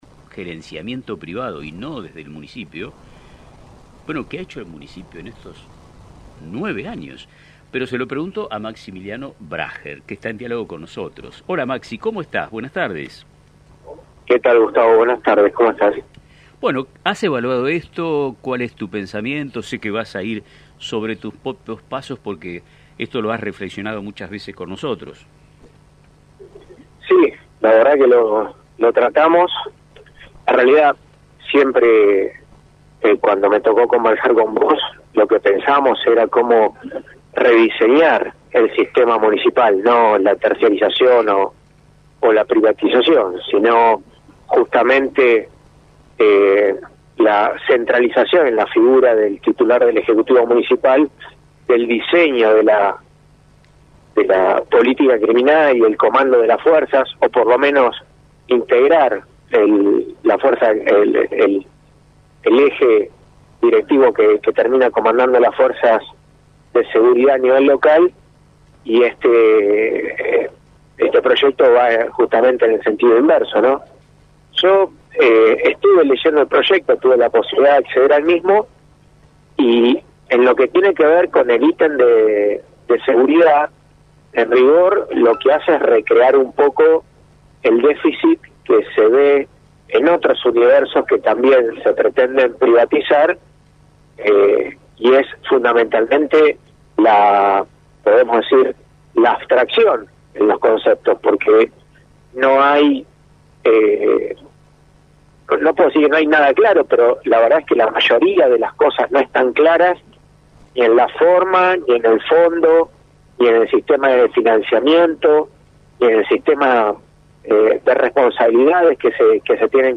Debate sobre la privatización de la seguridad en Pergamino – Radio Mon Pergamino